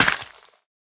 Minecraft Version Minecraft Version snapshot Latest Release | Latest Snapshot snapshot / assets / minecraft / sounds / block / suspicious_gravel / break6.ogg Compare With Compare With Latest Release | Latest Snapshot